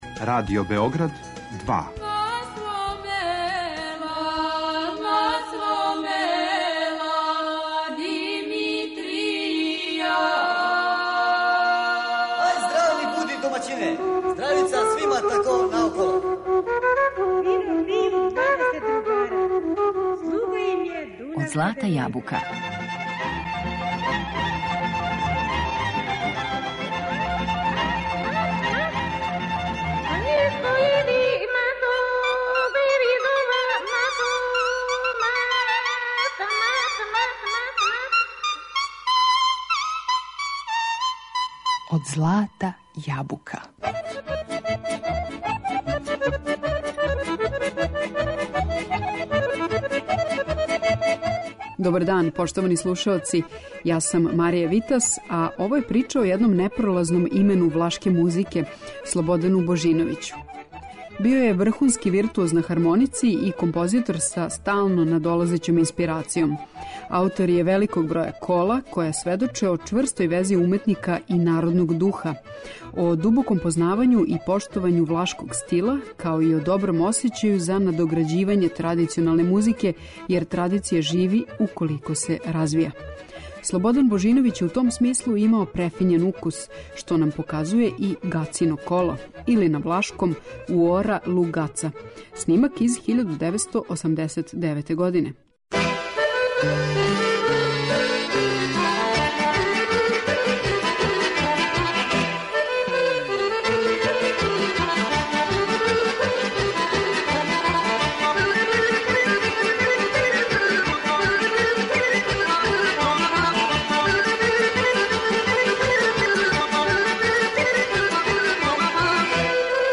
Били смо у Неготину, 17. и 18. априла.